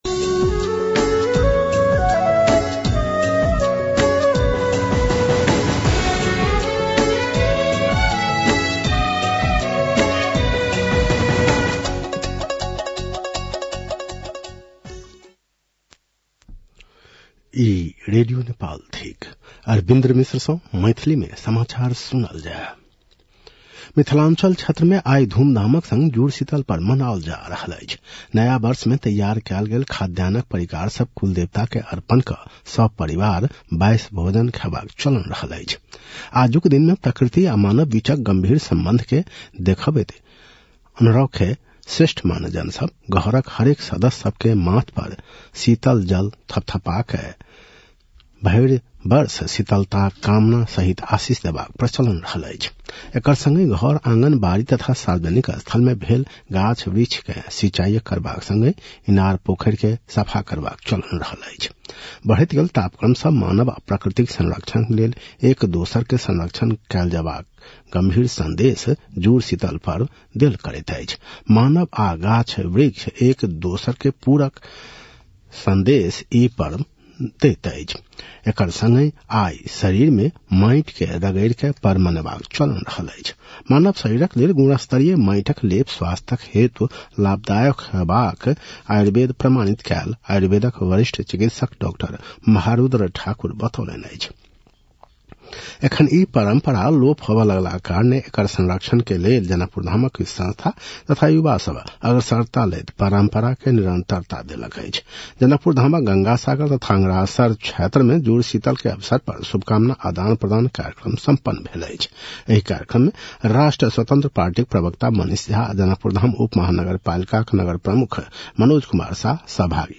मैथिली भाषामा समाचार : २ वैशाख , २०८३